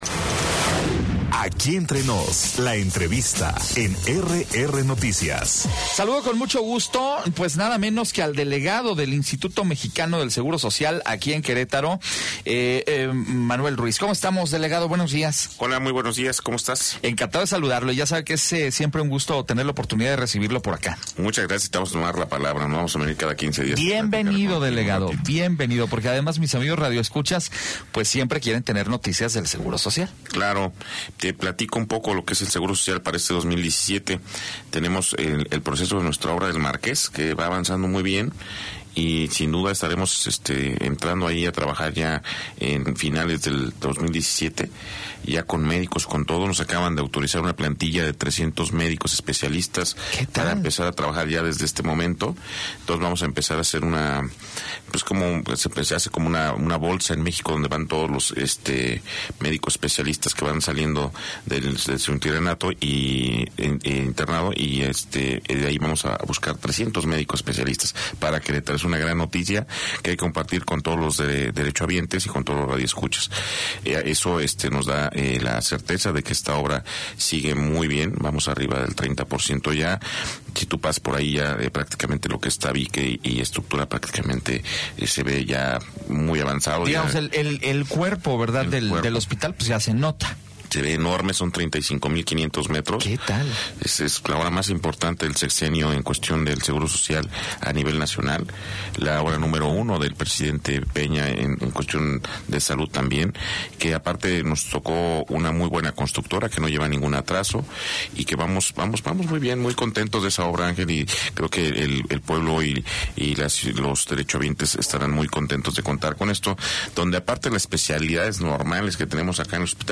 Entrevista con el delegado del IMSS Querétaro, Manuel Ruiz López - RR Noticias